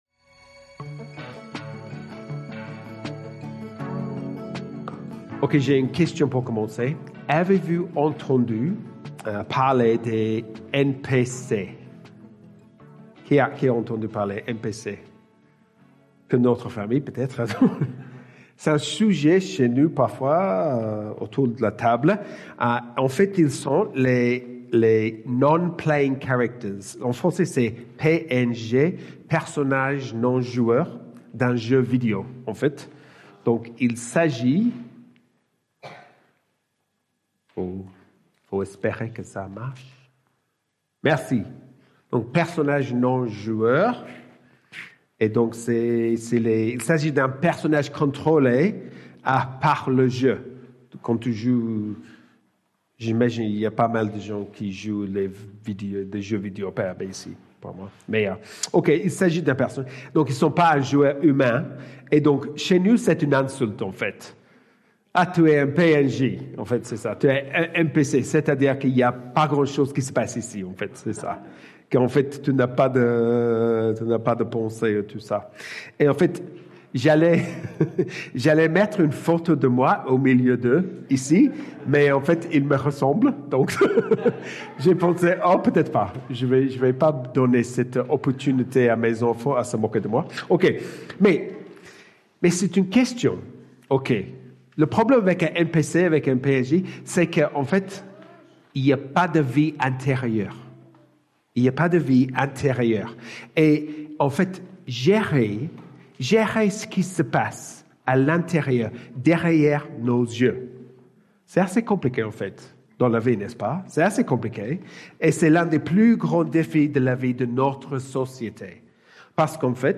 Predication12-29.mp3